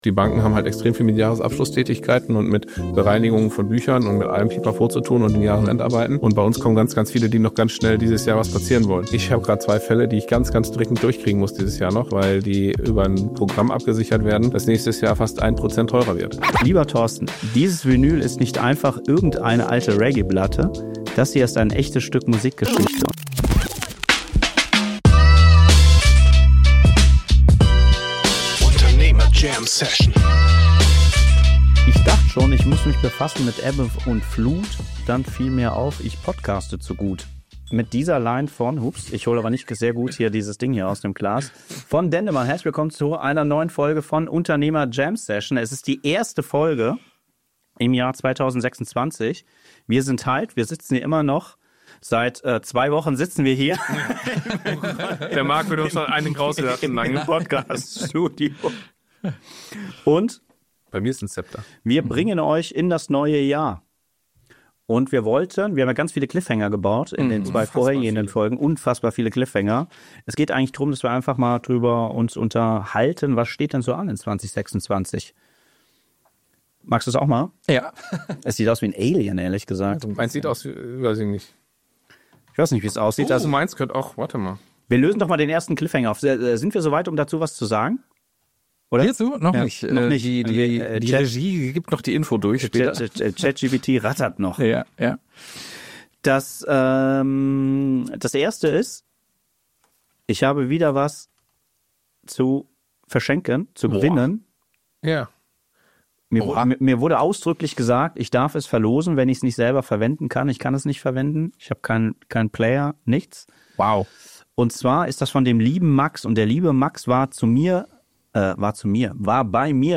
Statt großer Neujahrsparolen fühlt es sich eher nach ehrlichem Wohnzimmer-Talk an.